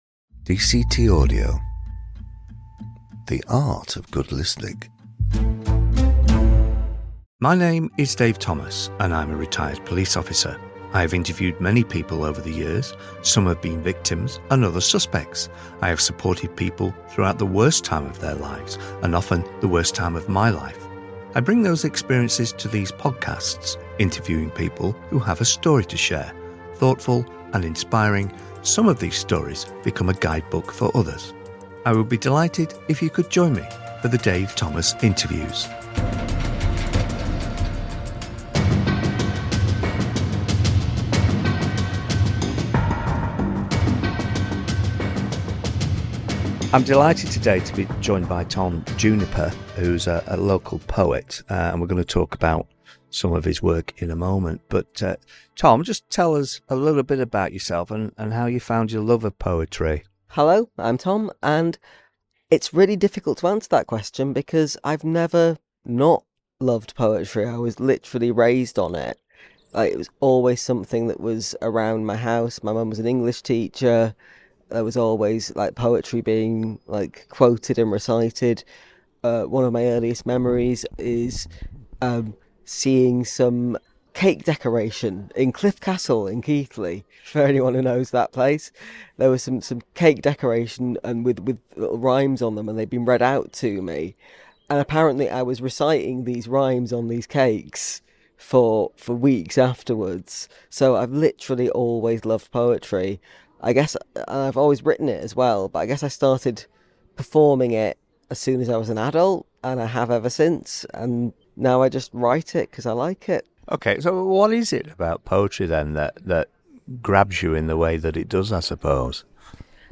Yorkshire Poet.